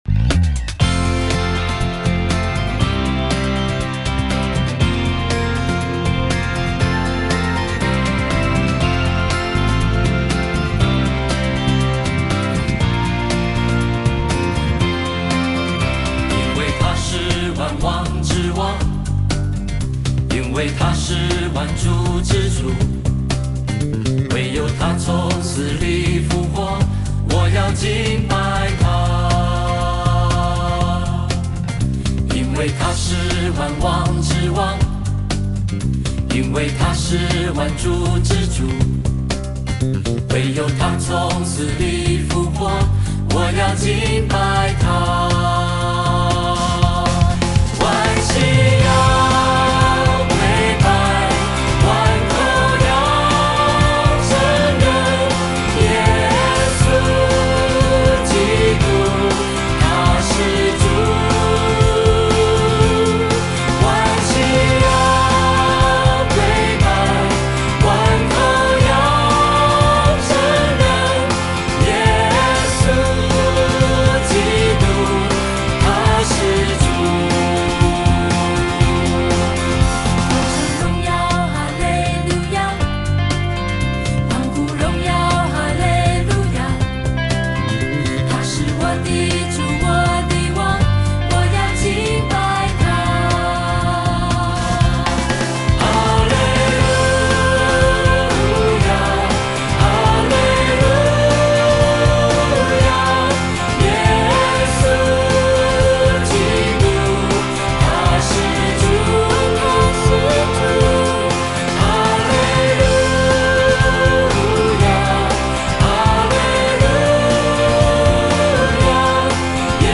mp3 原唱音樂